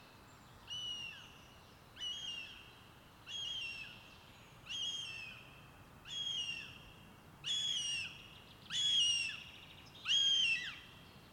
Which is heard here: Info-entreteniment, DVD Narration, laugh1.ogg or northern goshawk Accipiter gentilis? northern goshawk Accipiter gentilis